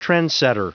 Prononciation du mot trendsetter en anglais (fichier audio)